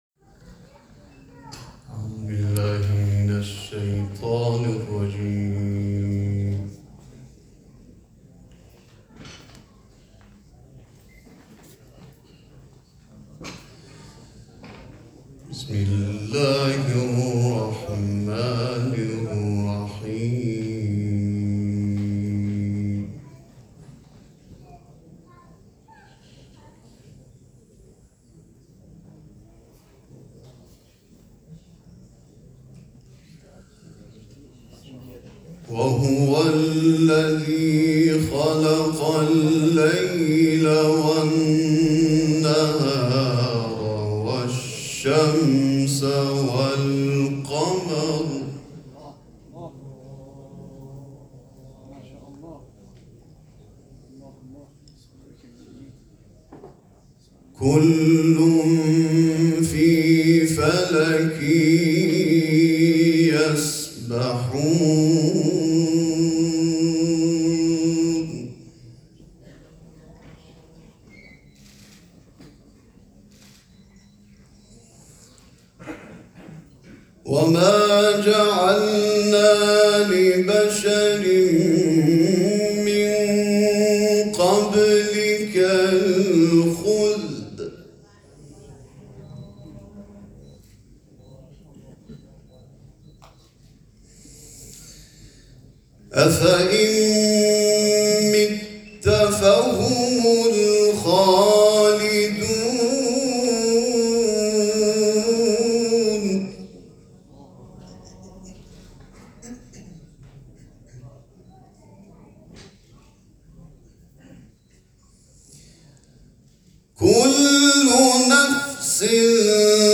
چهل و پنجمین دوره مسابقات سراسری قرآن
تلاوت قرآن